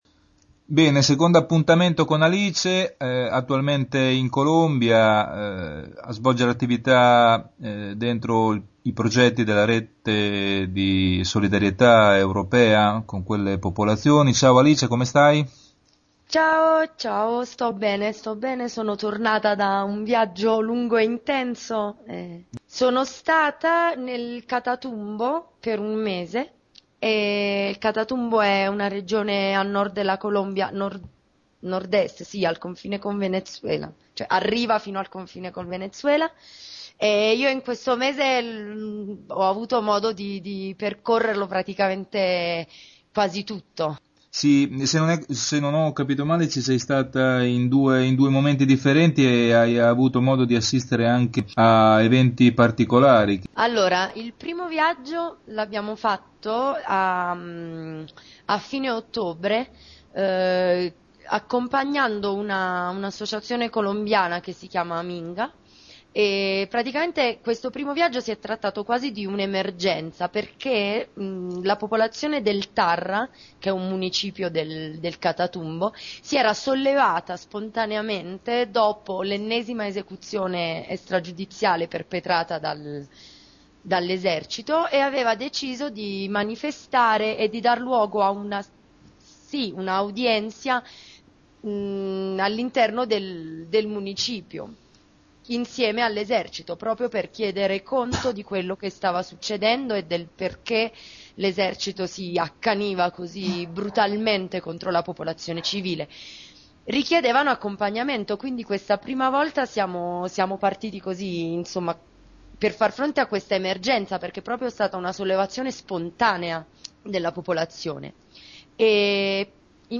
parte prima intervista